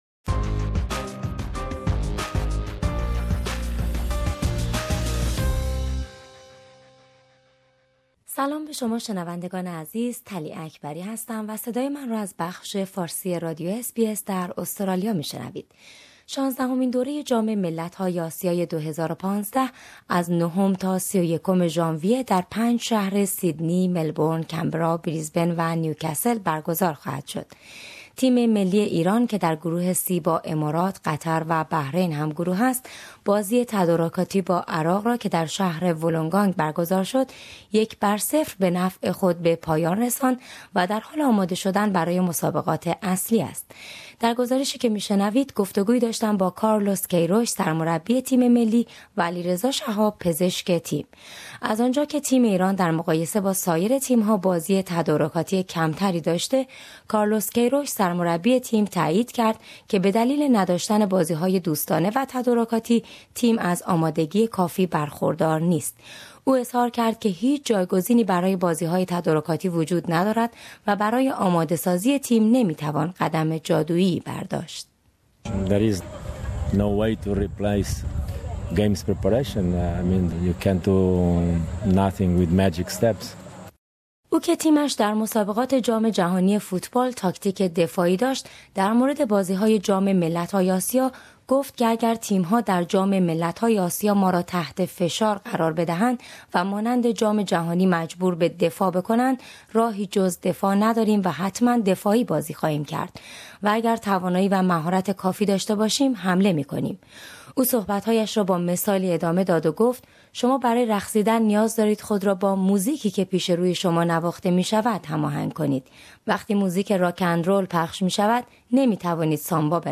Exclusive Interview with Carlos Queiroz